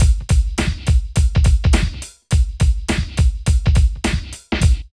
14 DRUM LP-L.wav